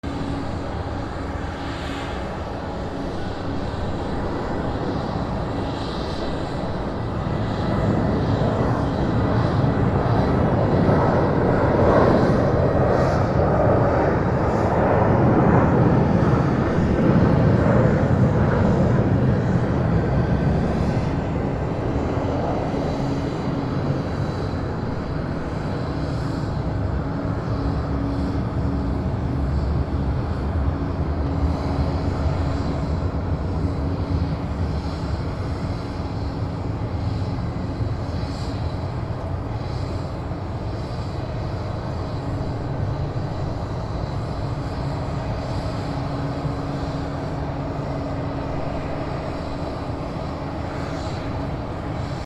飛行機 離陸
/ E｜乗り物 / E-80 ｜飛行機・空港
416 NT4 mix